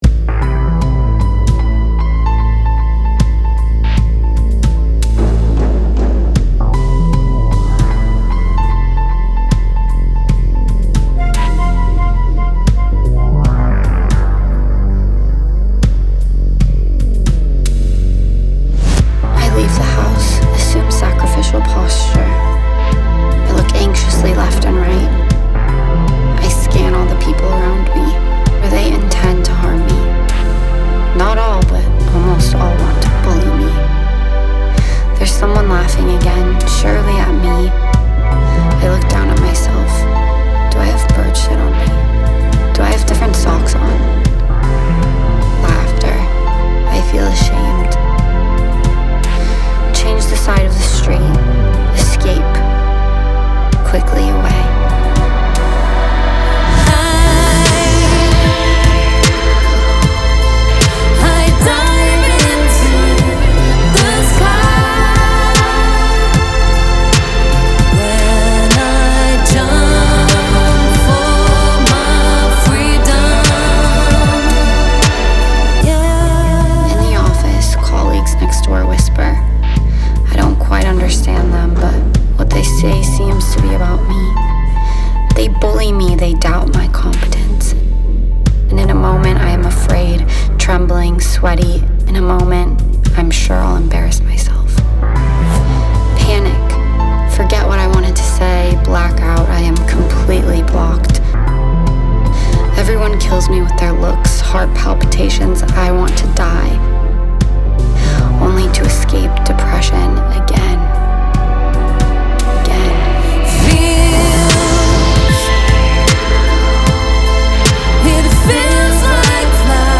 Vocal performance anonymous